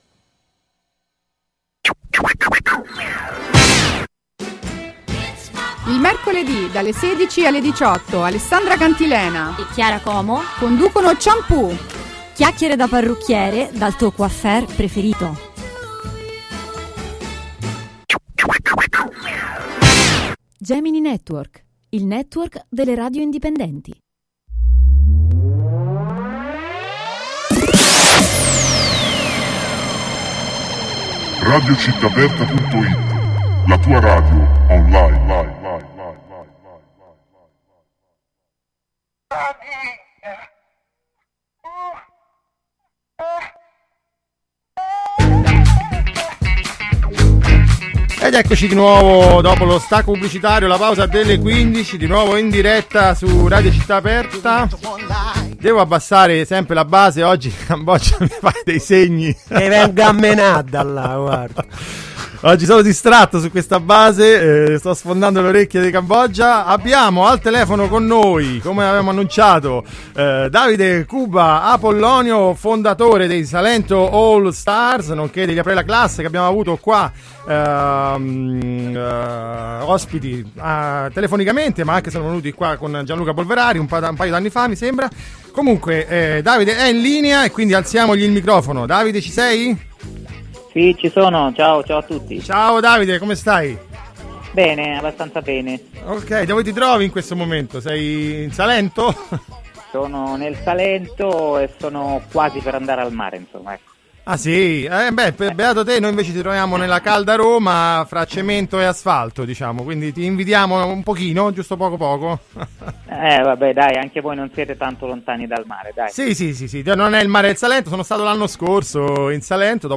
intervista-salento-all-stars.mp3